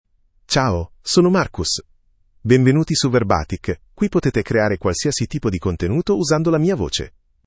MarcusMale Italian AI voice
Marcus is a male AI voice for Italian (Italy).
Voice sample
Listen to Marcus's male Italian voice.
Male
Marcus delivers clear pronunciation with authentic Italy Italian intonation, making your content sound professionally produced.